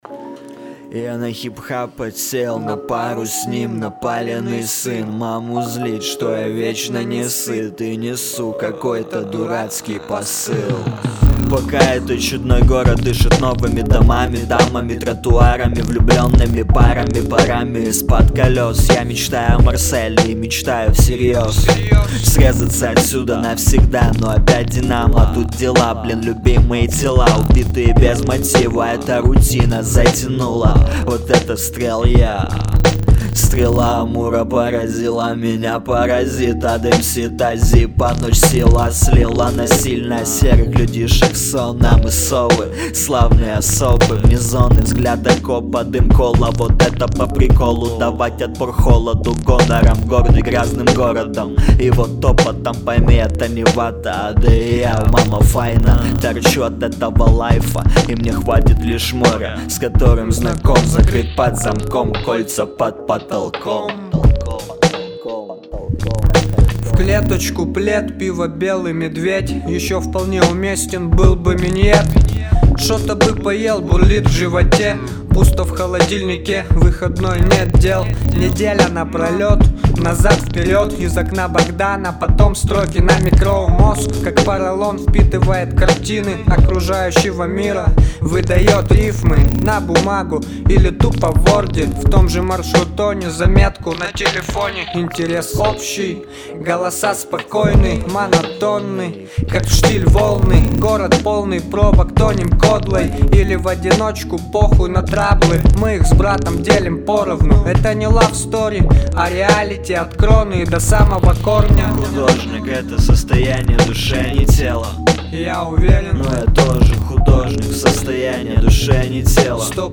Яркий андерграунд-проект из города-героя Одессы.
Очень яркий и талантливый хип-хоп.